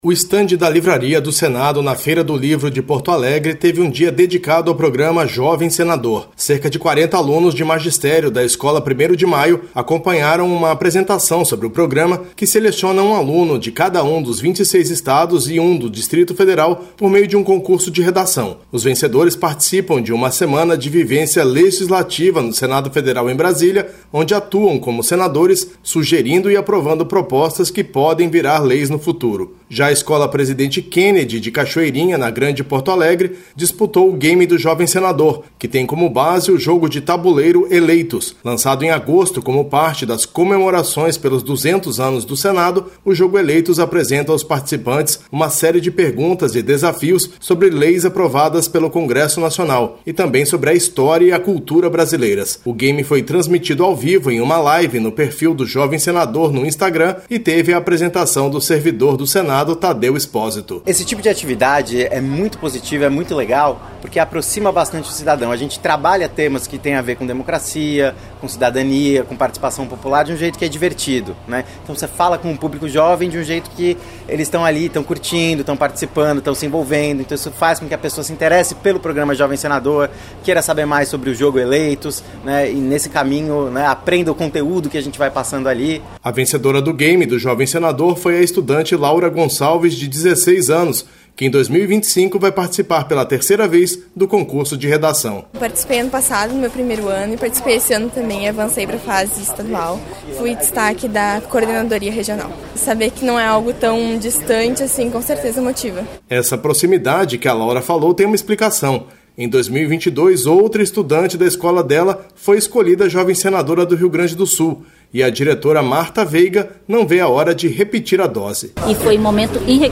O Programa Jovem Senador foi destaque nesta terça-feira (12) no estande do Senado na Feira do Livro de Porto Alegre. Alunos de duas escolas públicas tiveram a oportunidade de conhecer melhor o programa e participar de um jogo de perguntas que foi transmitido ao vivo pela internet. Todos os anos, o Programa Jovem Senador seleciona um estudante de ensino médio de cada um dos 26 estados e do Distrito Federal, por meio de um concurso de redação.